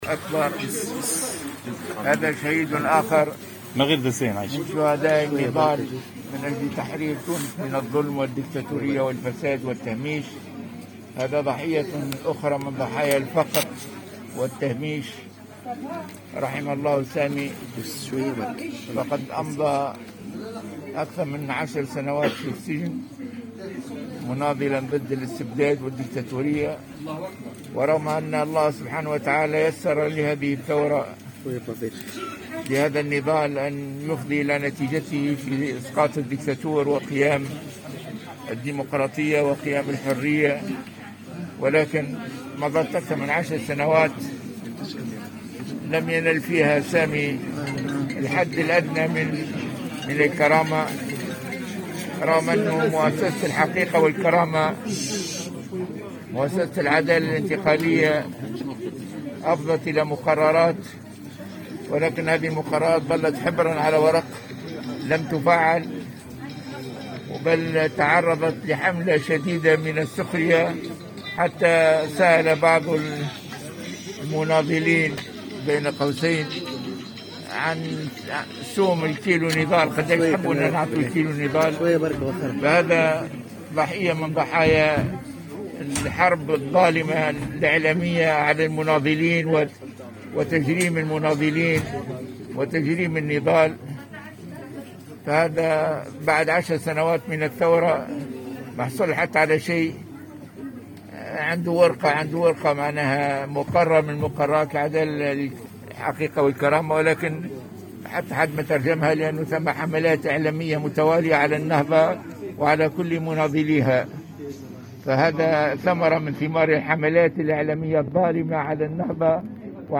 أوّل تصريح للغنوشي بعد حريق مقر النهضة